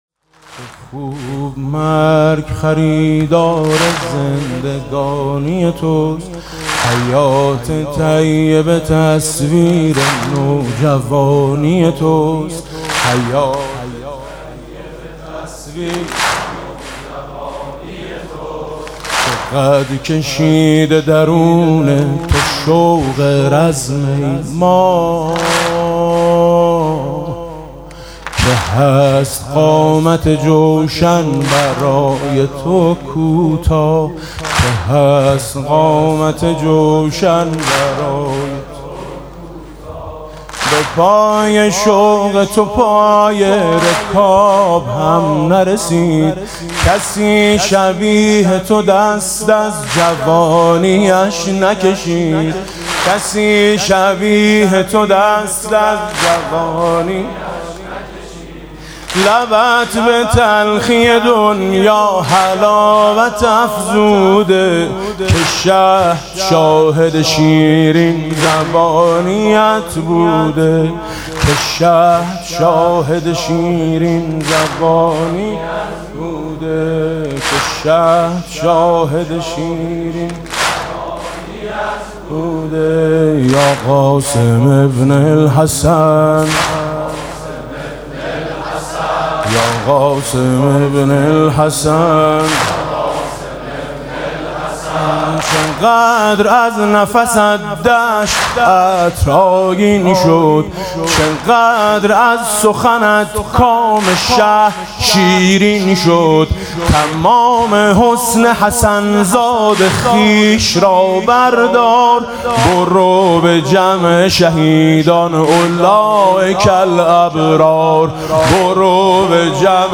مراسم عزاداری شب ششم محرم ۱۴۰۳ با صدای میثم مطیعی